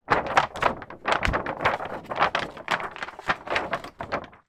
Звуки плаката
Шум шелеста большого плаката